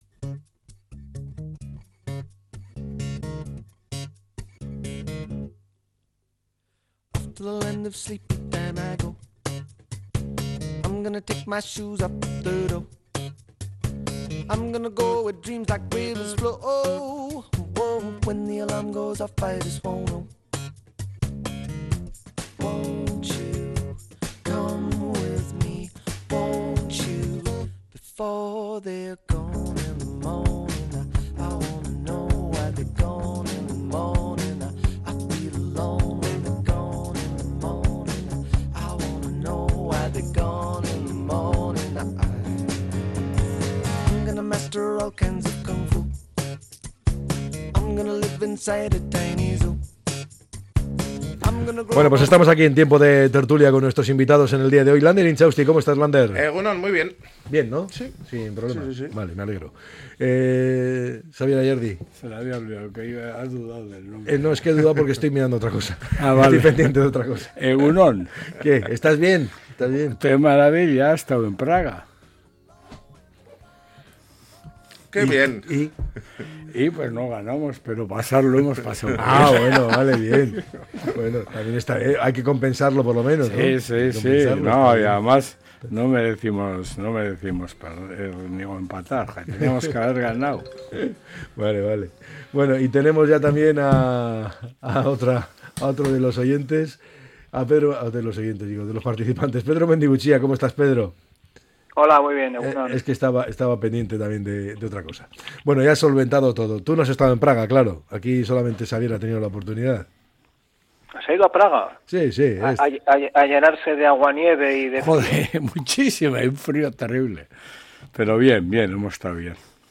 analiza a diario diferentes temas de actualidad con sus tertulianxs